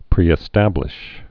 (prēĭ-stăblĭsh)